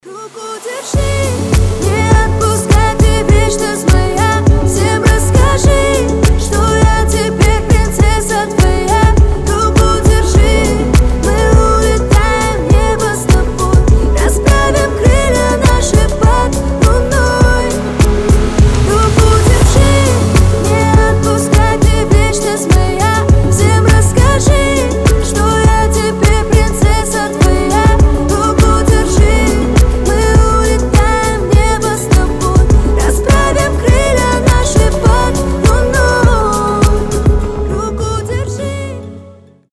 • Качество: 320, Stereo
поп
мелодичные